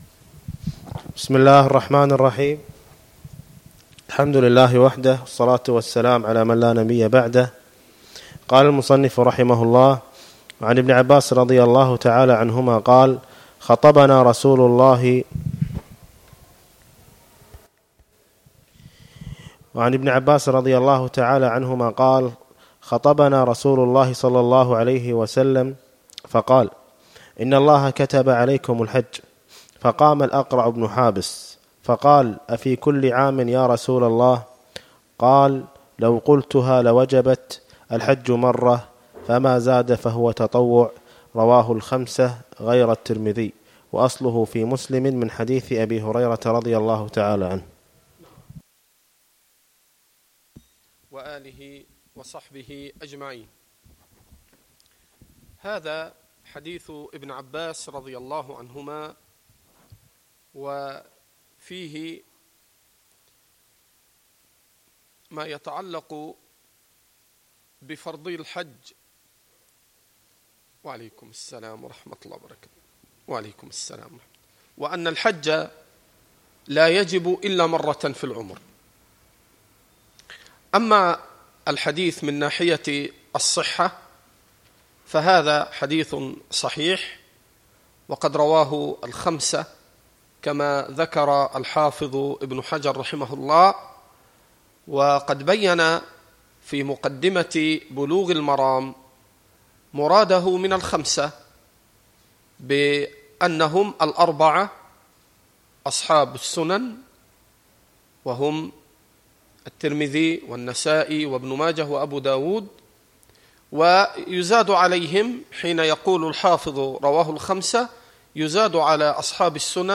الدروس والمحاضرات